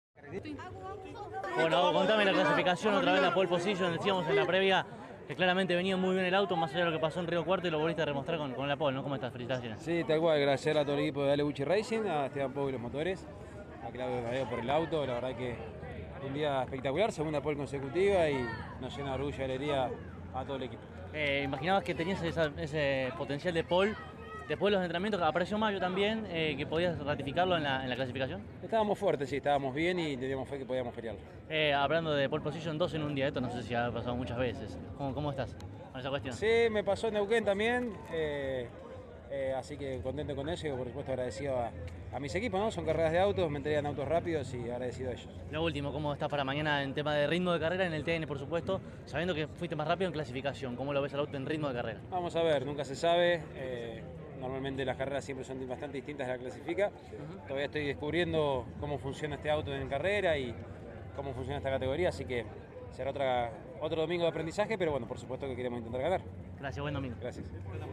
CÓRDOBA COMPETICIÓN pudo dialogar con el propio Agustín Canapino, y esto decía: